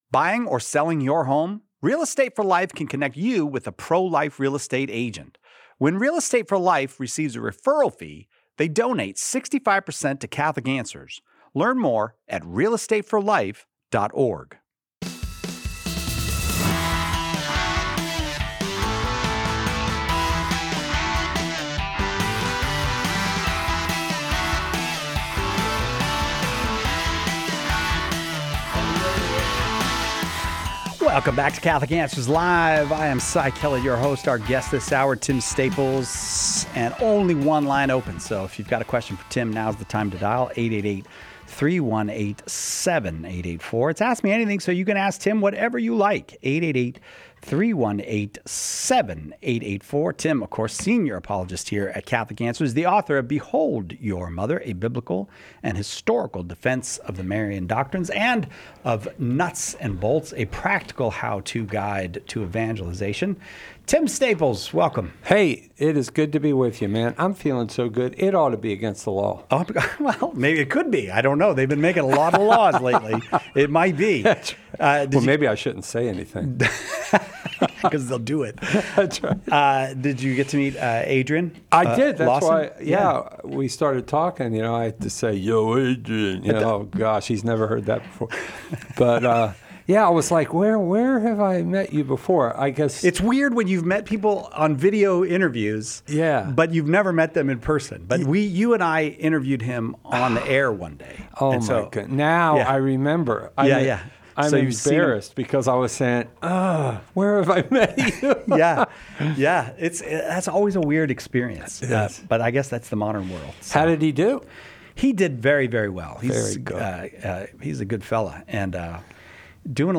Topics include the Church’s position on dividing ashes, relics, and organ donation, and the meaning of a line from the “Golden Arrow” prayer. A caller asks why some OCIA participants still struggle with Marian dogmas.